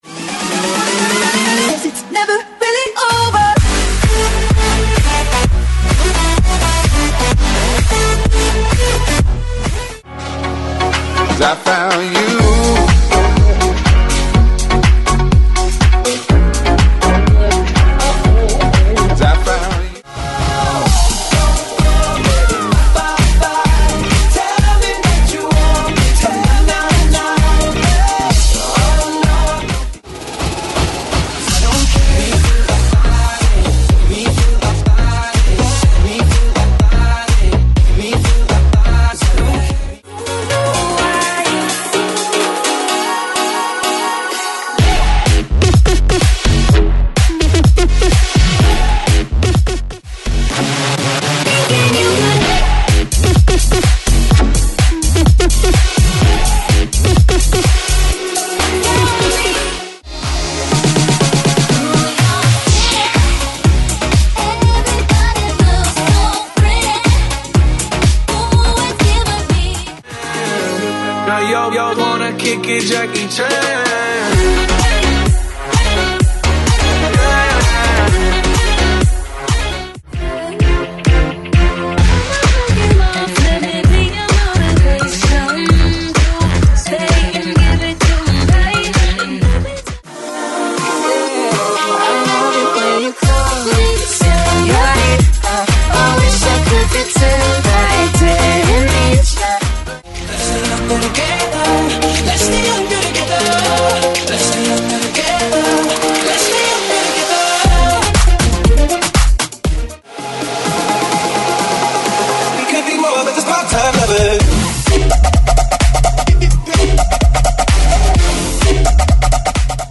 AFROBEAT , BOOTLEG , TOP40